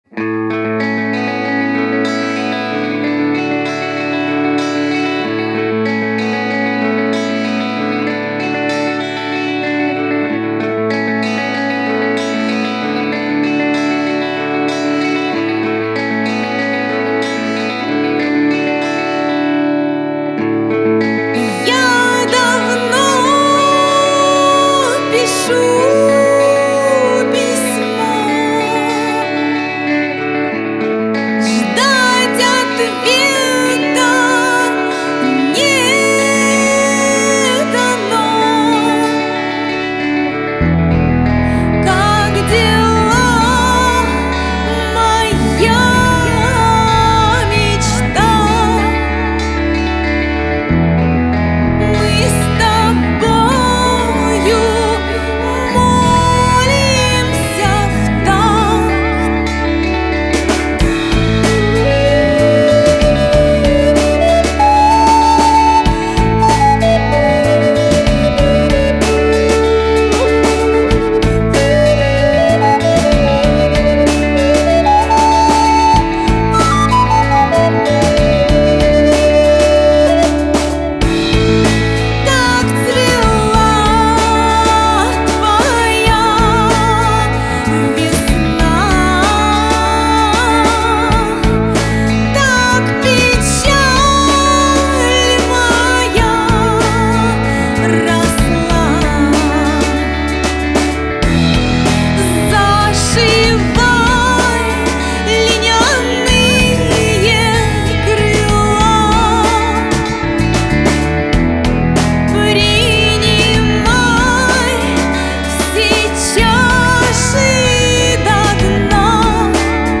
Genre: Folklore.